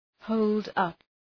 {‘həʋldʌp}